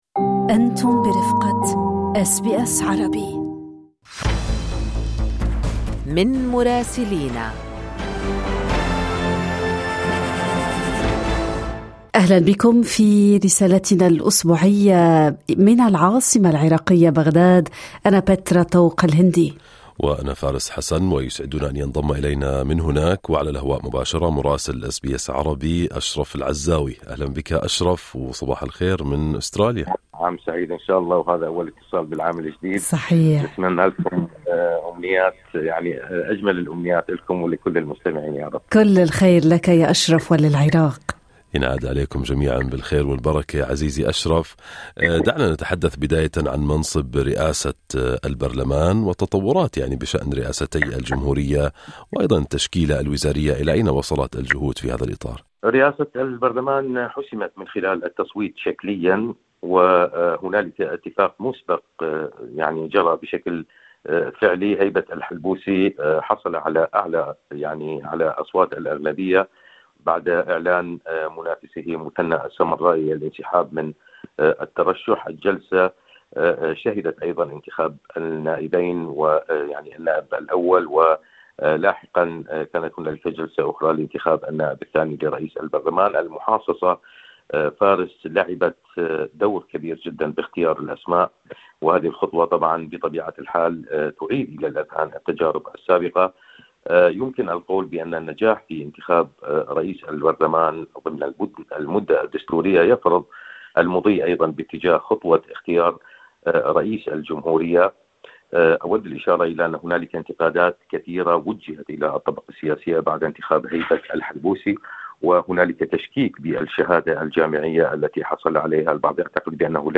تتناول الرسالة الأسبوعية من بغداد أبرز التطورات السياسية والأمنية في العراق، في ظل مرحلة دقيقة تتقاطع فيها الاستحقاقات الدستورية مع التحديات الإقليمية.